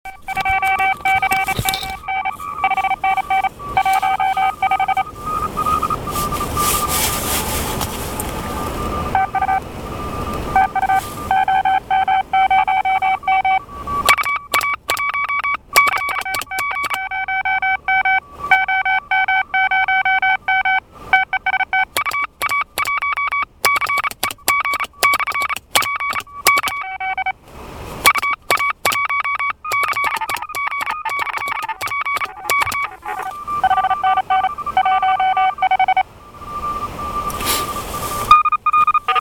Я отметился в CQ WW Contest из лесов- полей, QRP/p. Естественно никакого потрясающего результата, его и быть не могло!